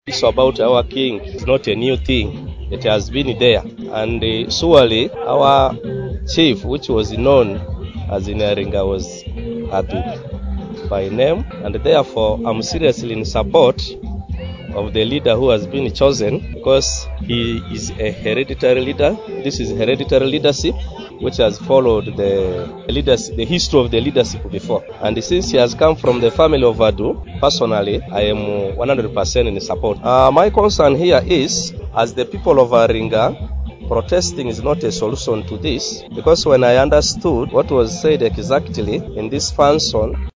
Local residents also expressed their joy and excitement as they witnessed the historic installation.